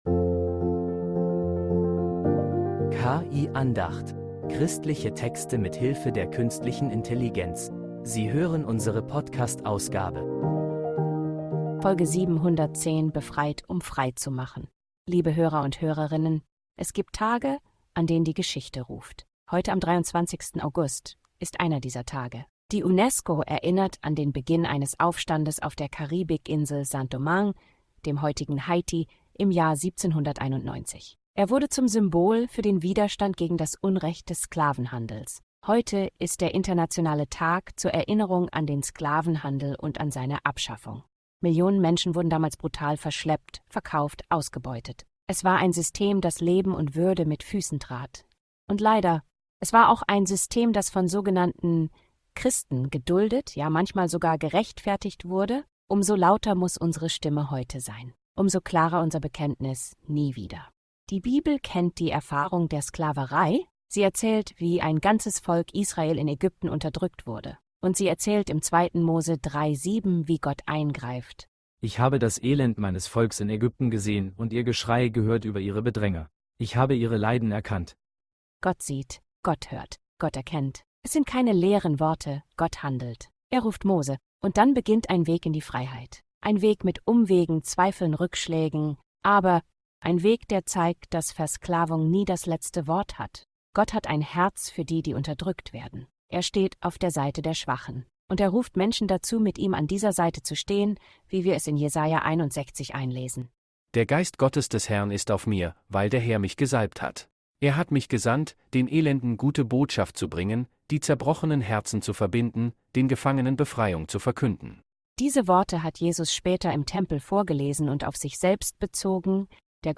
klare Predigt über Freiheit.